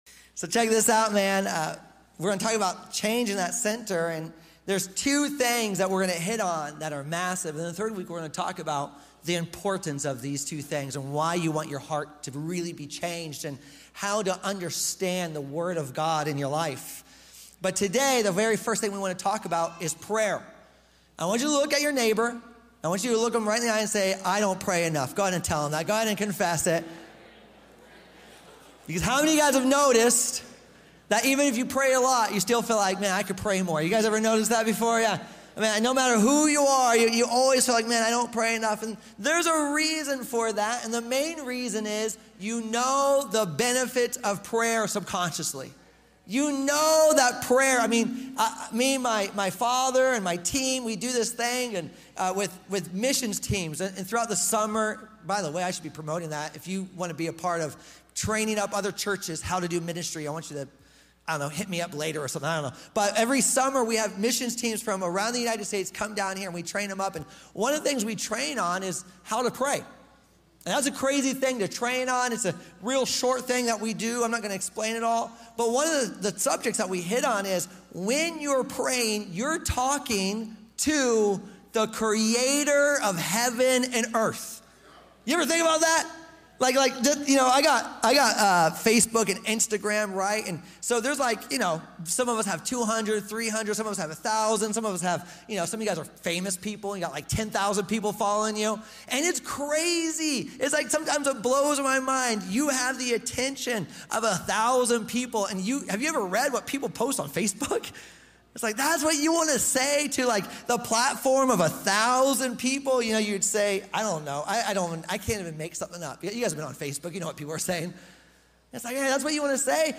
We all feel it: “I don’t pray enough.” This message reframes prayer from a quick ritual into a life-replacing rhythm that reshapes your heart.